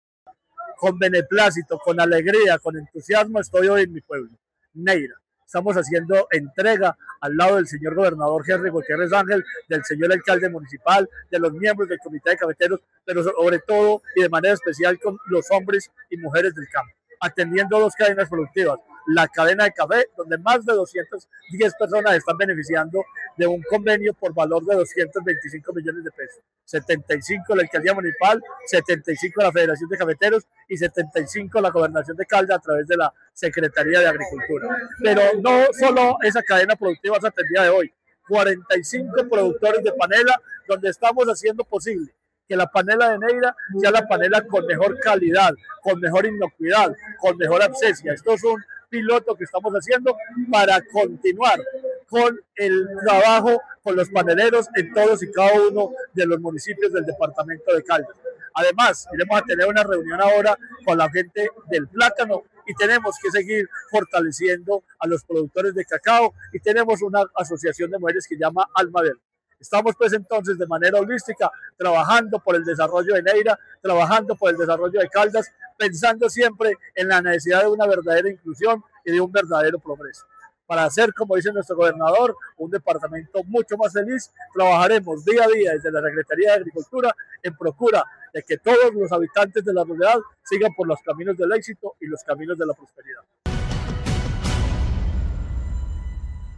Marino Murillo Franco, secretario de Agricultura y Desarrollo Rural de Caldas